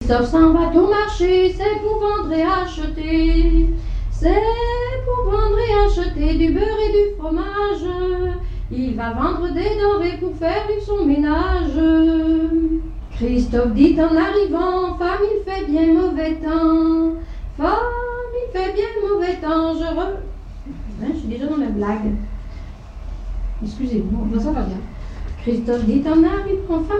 danse : branle : avant-deux
airs de danses et chansons traditionnelles
Pièce musicale inédite